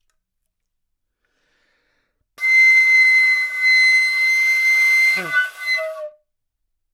长笛单音（吹得不好） " 长笛C6不好的丰富性
描述：在巴塞罗那Universitat Pompeu Fabra音乐技术集团的goodsounds.org项目的背景下录制。